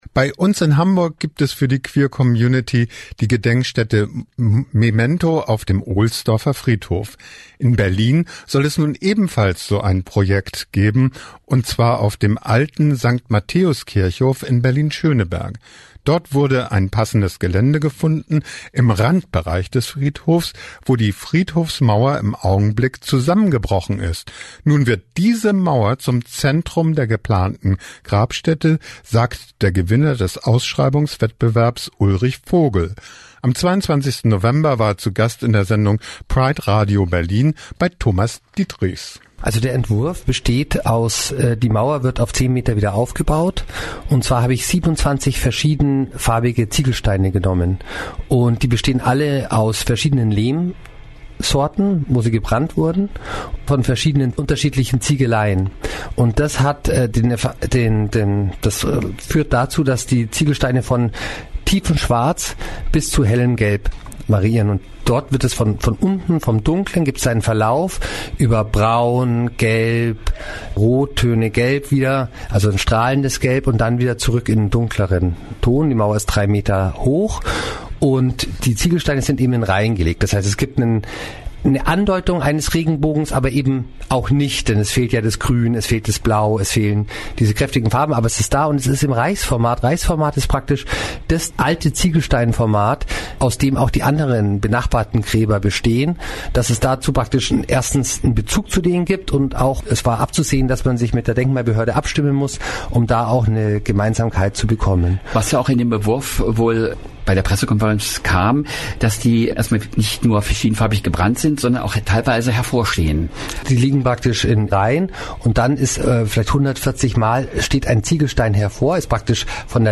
Ein digitales Gedenkbuch soll die Geschichten der Verstorbenen bewahren. – Aus einem Studiogespräch von Prderadio Berlin.